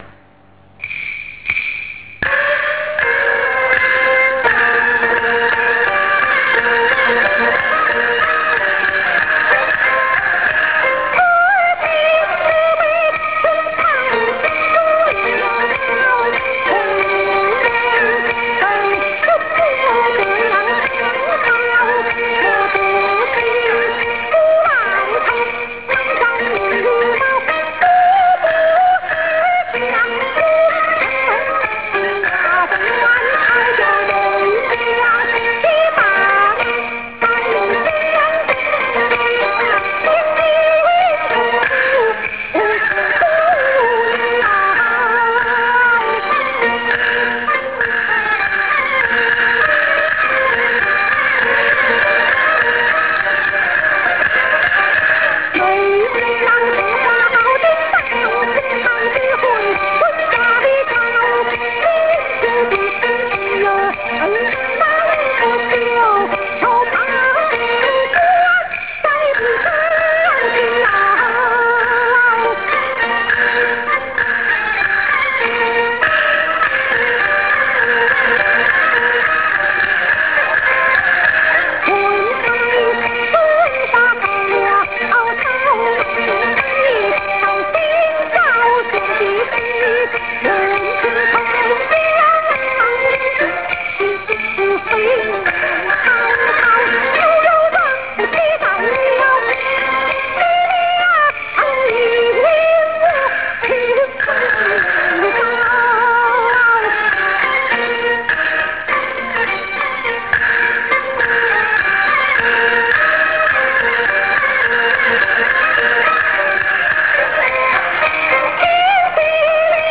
2nd aria by LI Shi-Ji (in Real-Audio format);